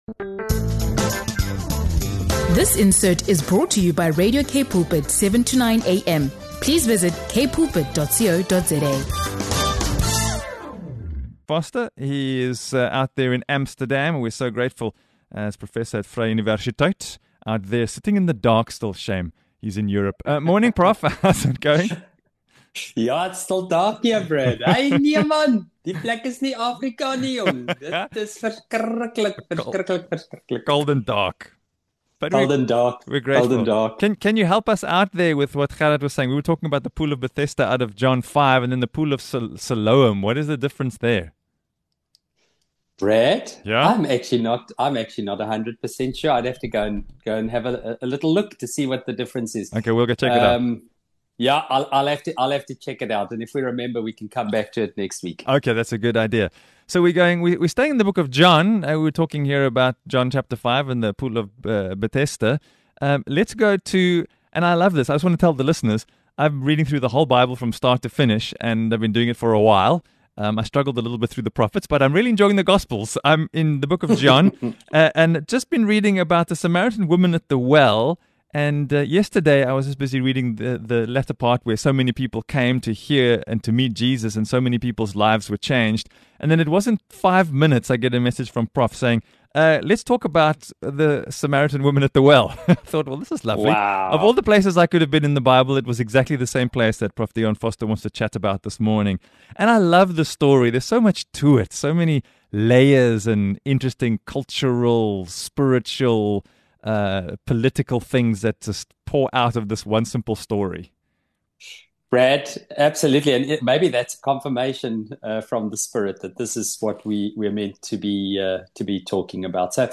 Discover how Jesus challenges societal norms, restores dignity, and offers the "water of life" to those on the margins. This conversation explores themes of identity, purpose, and divine love, offering deep insights that can transform the way we view ourselves and others.